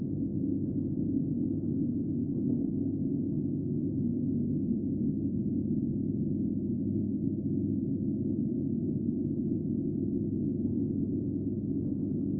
白噪声楼道2.ogg